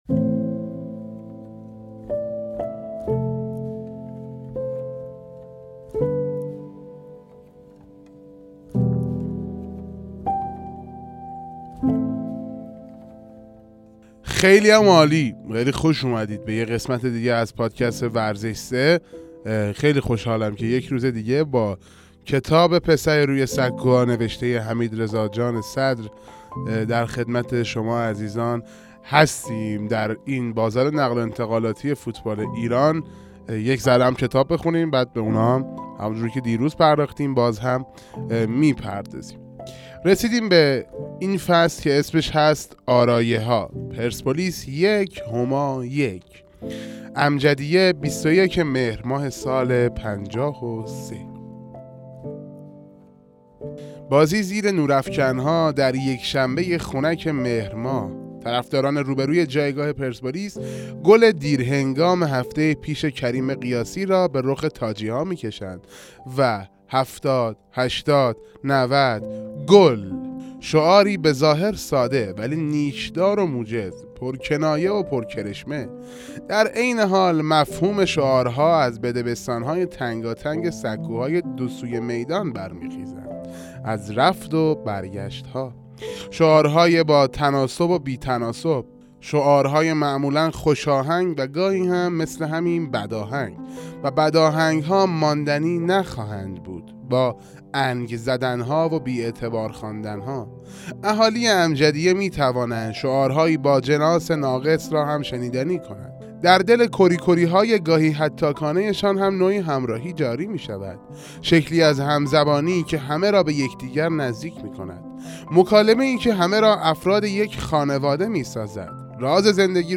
7 دانلود اشتراک گذاری 1 323 پادکست 29 اردیبهشت 1404 ساعت 14:36 ویتامین سه کتاب صوتی پسری روی سکوها (قسمت سی و نهم) کتاب پسری روی سکوها وقایع نگاری چهاردهه فوتبال ایران است که در قالب رمان روایت شده است.